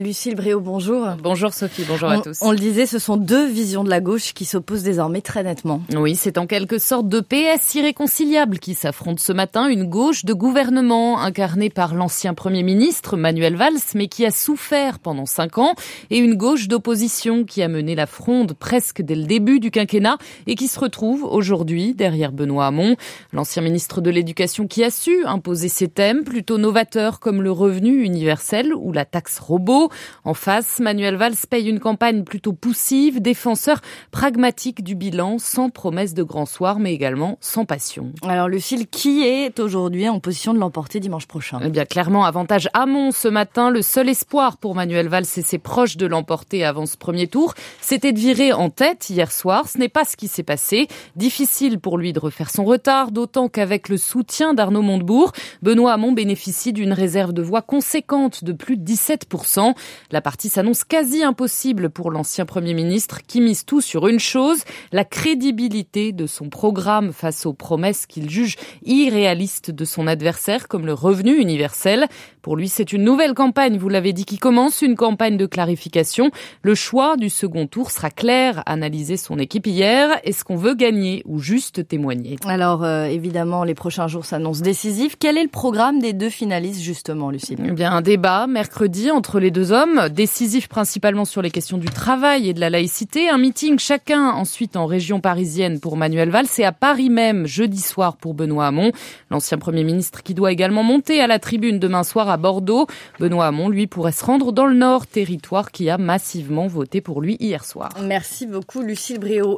diffusé ce matin dans le journal de 7h